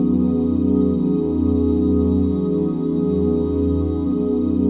OOH EMAJ9.wav